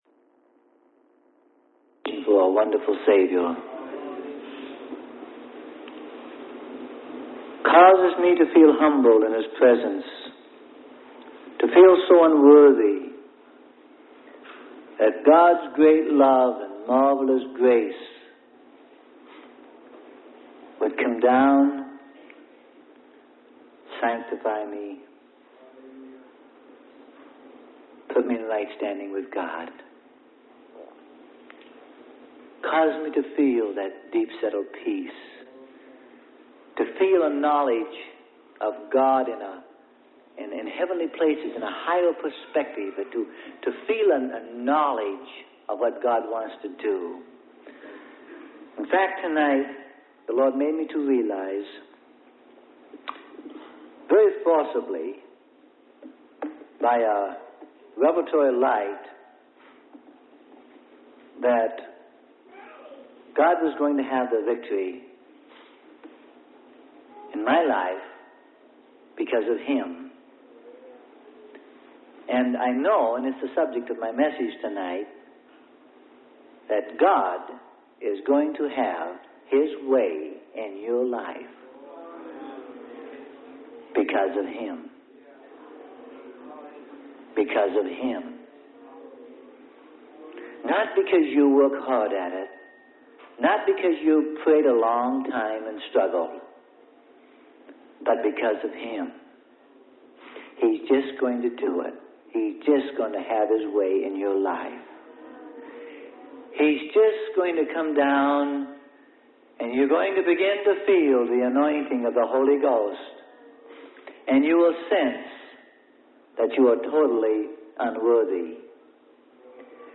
Sermon: Boldness To Receive.